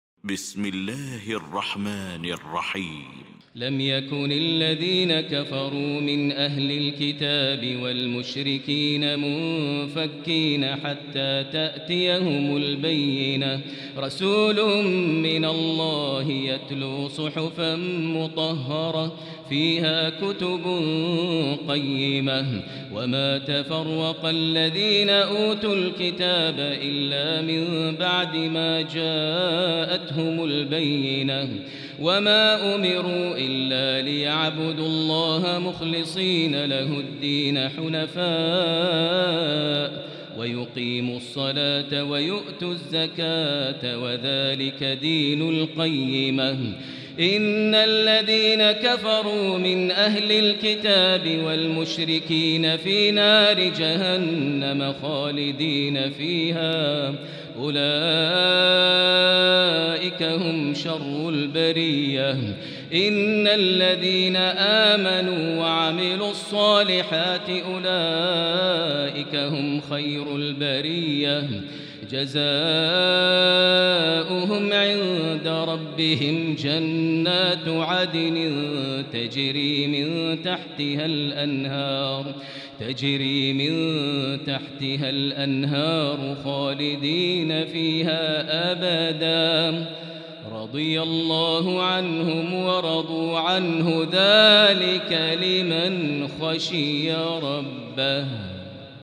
المكان: المسجد الحرام الشيخ: فضيلة الشيخ ماهر المعيقلي فضيلة الشيخ ماهر المعيقلي البينة